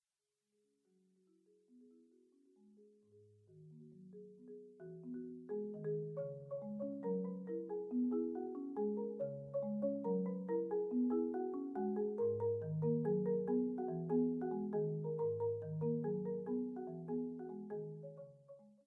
Marimba Share zol_ay